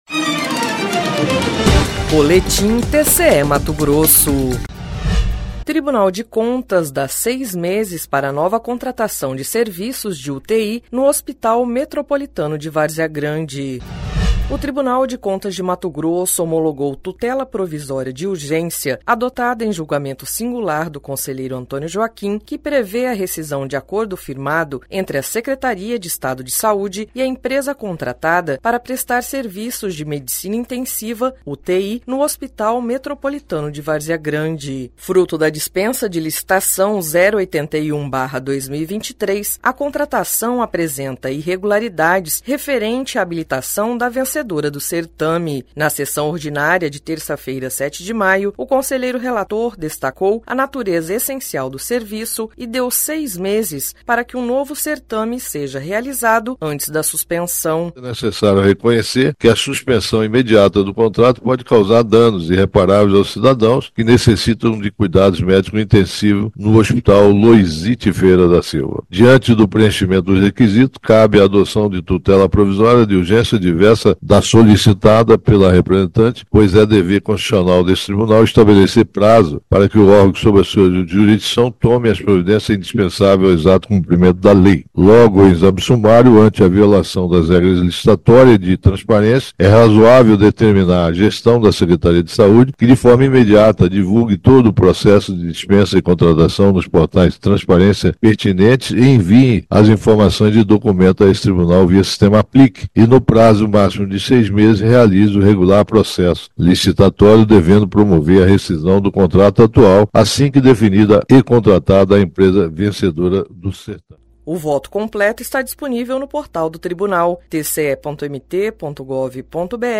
Sonora: Antonio Joaquim – conselheiro do TCE-MT